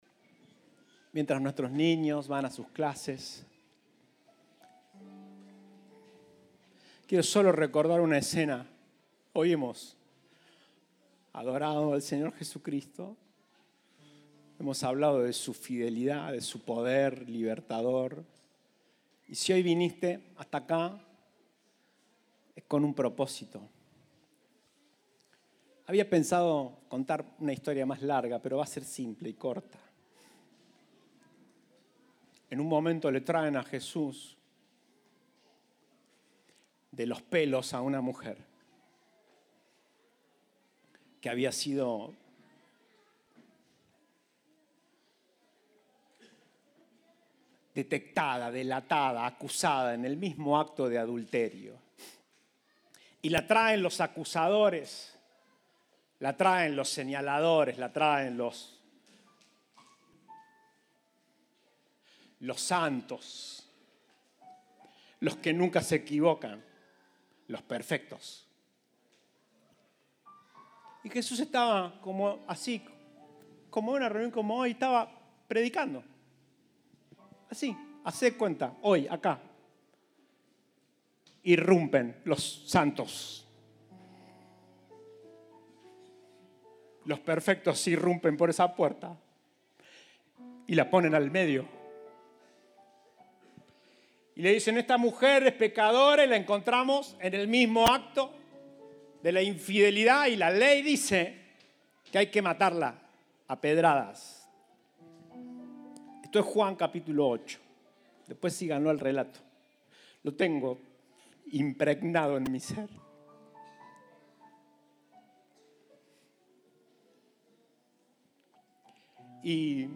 Compartimos el mensaje del Domingo 25 de Septiembre de 2022.